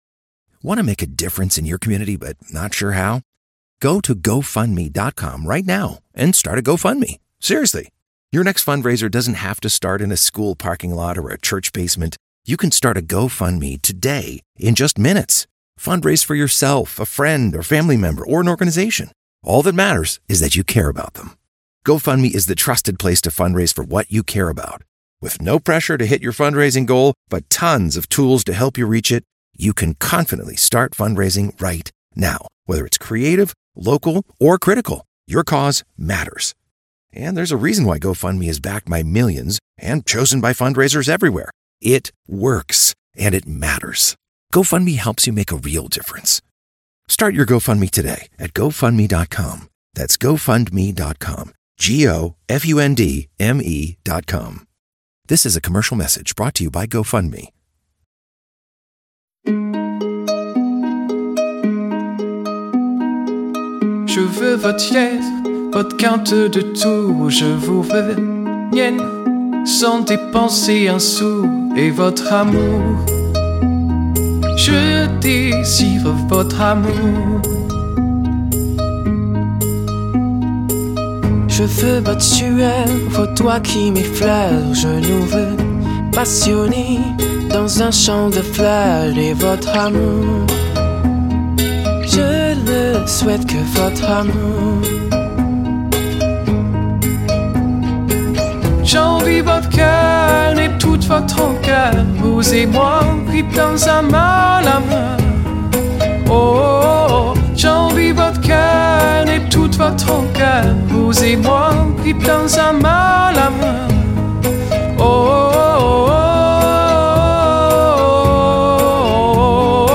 una cover in francese rinascimentale
a cover in Renaissance French